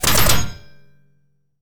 combat / weapons / mgun / metal2.wav
metal2.wav